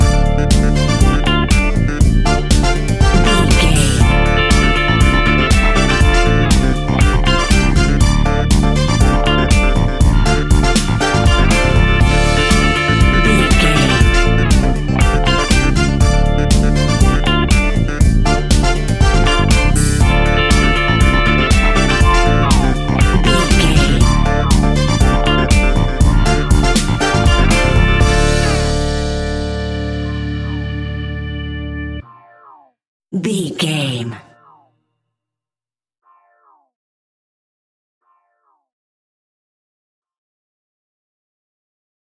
Aeolian/Minor
D
funky
groovy
uplifting
driving
energetic
strings
brass
bass guitar
electric guitar
electric organ
synthesiser
drums
funky house
disco house
electro funk
upbeat
synth leads
Synth Pads
synth bass
drum machines